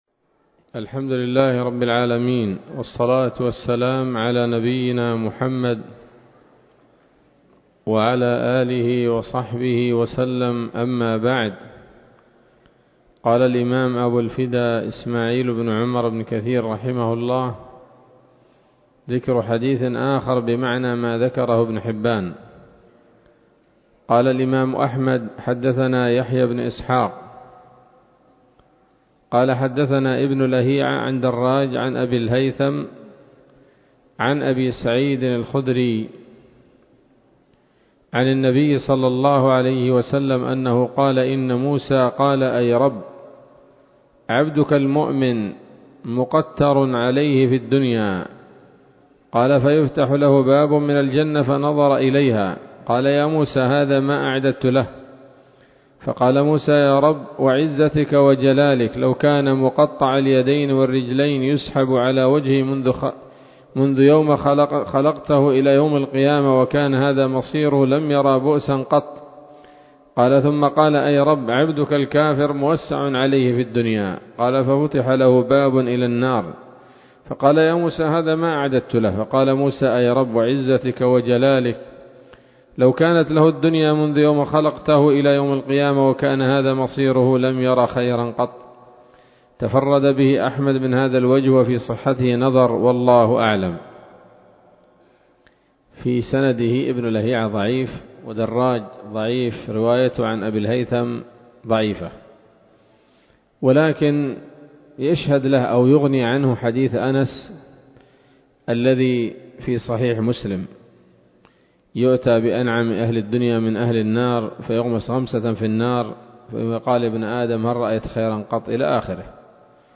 ‌‌الدرس المائة من قصص الأنبياء لابن كثير رحمه الله تعالى